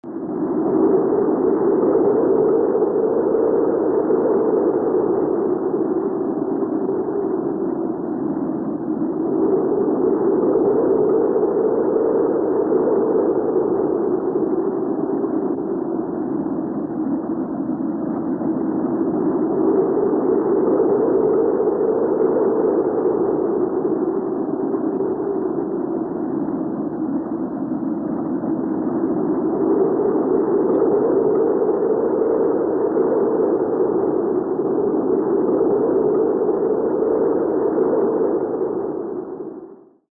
Подборка передает всю мощь природного явления: свист ветра, грохот падающих предметов, тревожную атмосферу.
Шум надвигающегося урагана